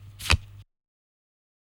効果音
少し効果音を録音しました。
カードを引く